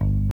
Bass (8).wav